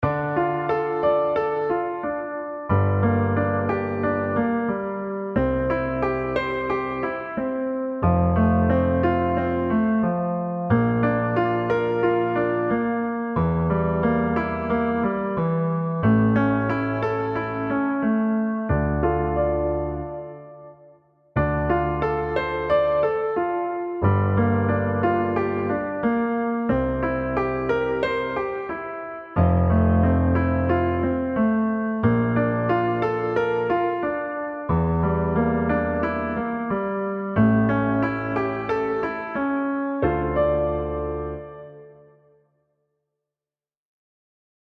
Descending fifths sequence step by step. Piano practice playing freely without sheet music just chords.
Pratice with the Gm ( Gmin) sequence